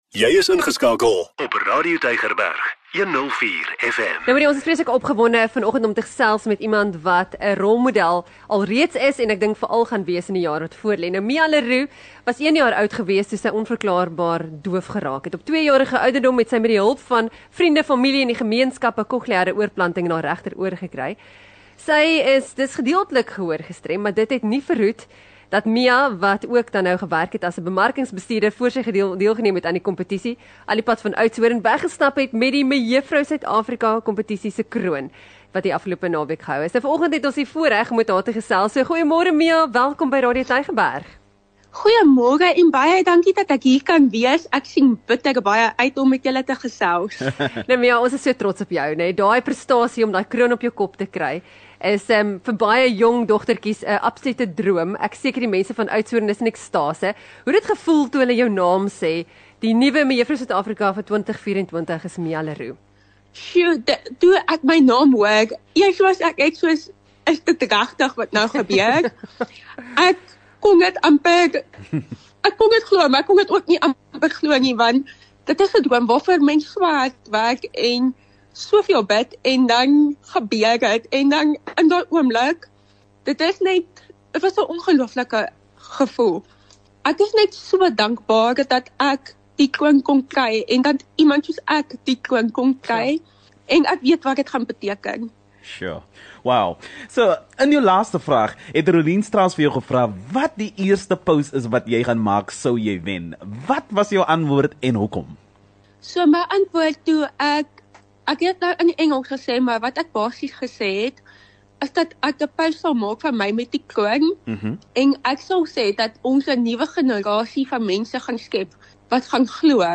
12 Aug Die Real Brekfis gesels met nuwe Mej. SA, Mia Le Roux